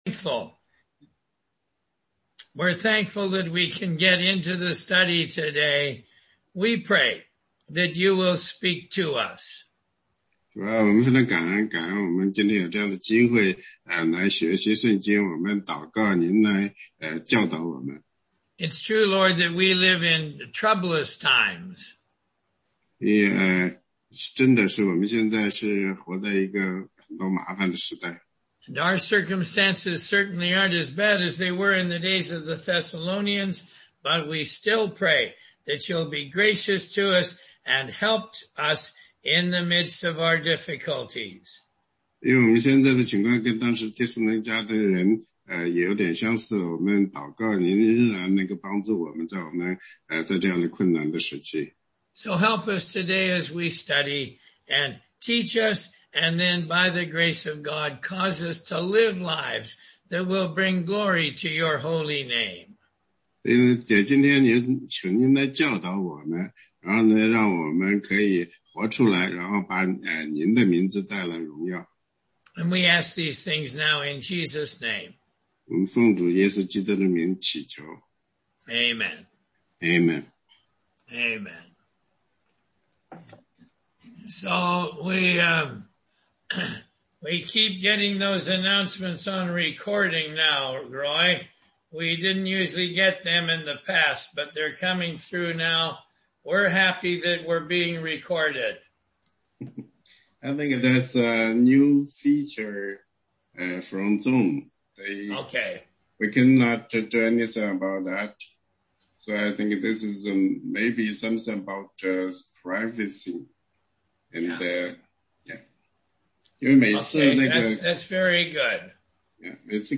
答疑课程